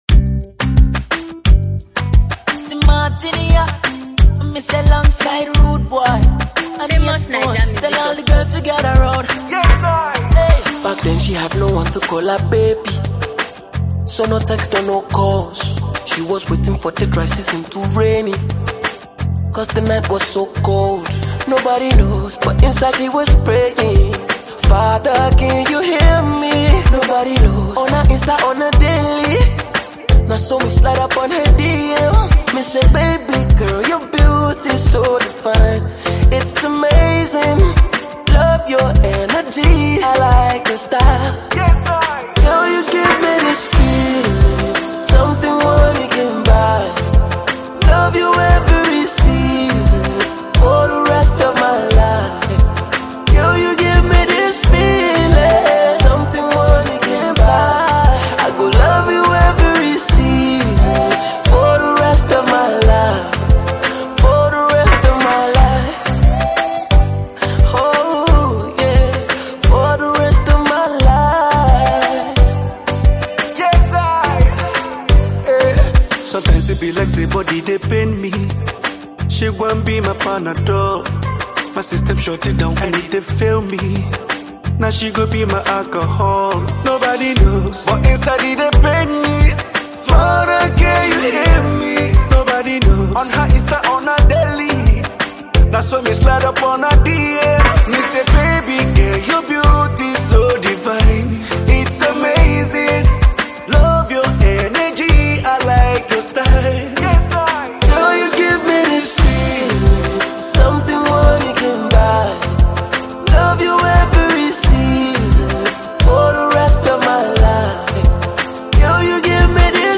reggae-enlivened track